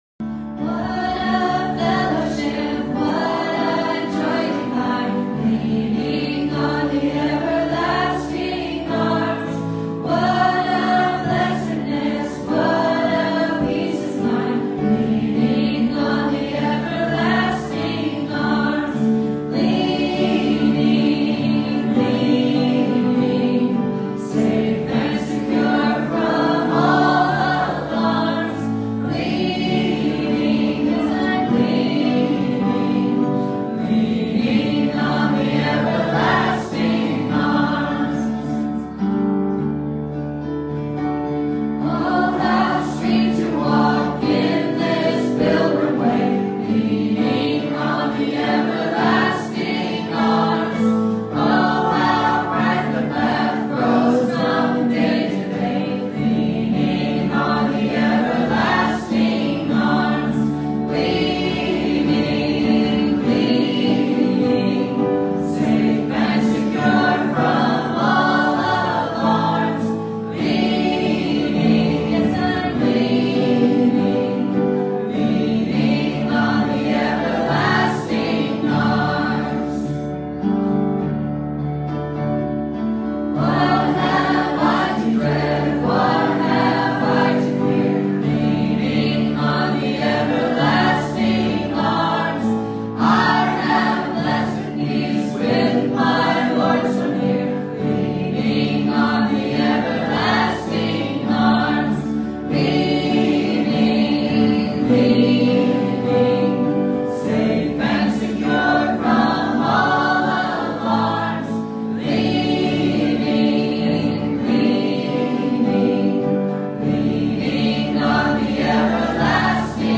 Romans 11:7-24 Service Type: Sunday Morning « Mystery